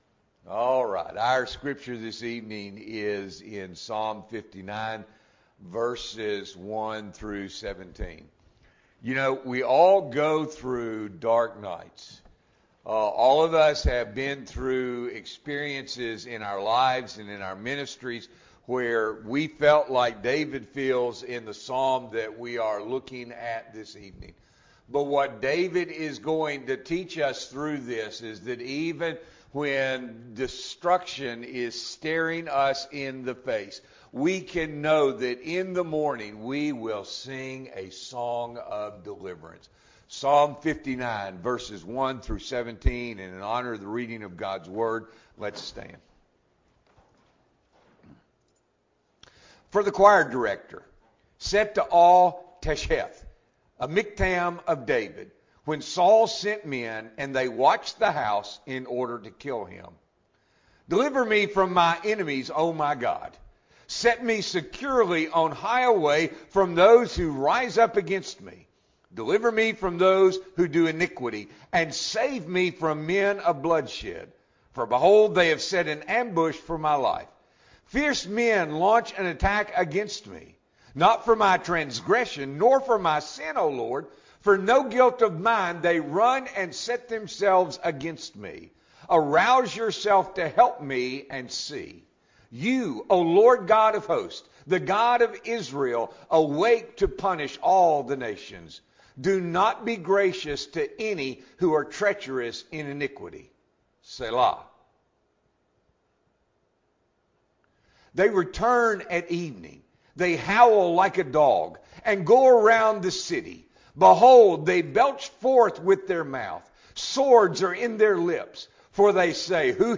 December 10, 2023 – Evening Worship